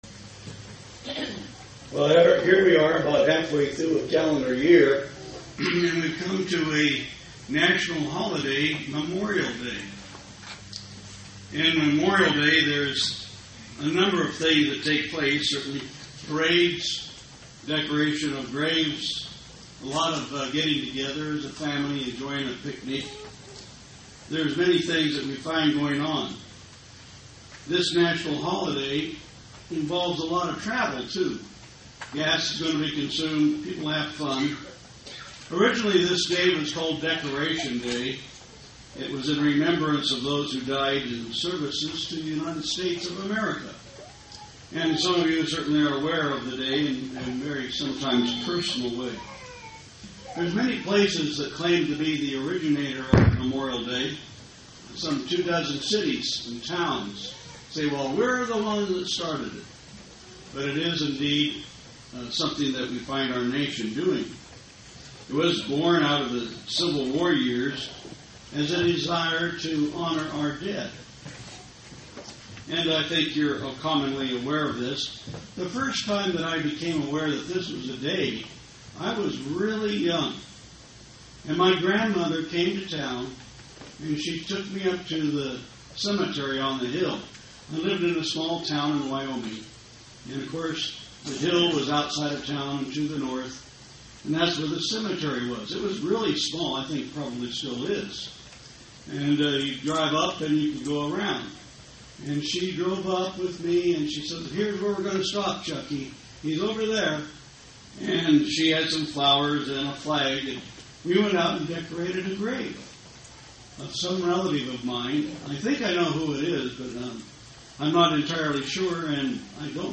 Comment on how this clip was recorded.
Given in Tampa, FL St. Petersburg, FL